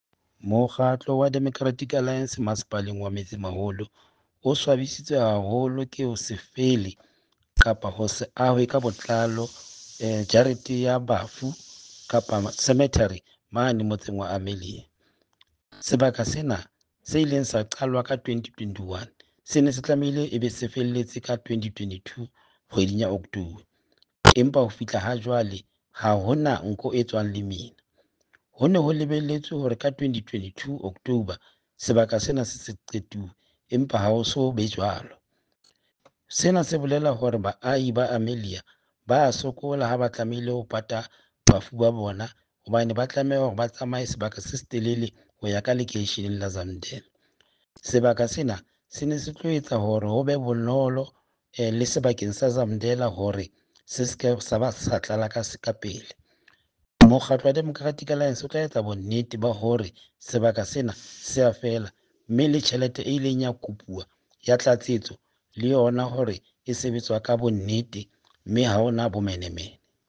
Sesotho soundbites by Cllr Stone Makhema. Please see the pictures here, here, and here.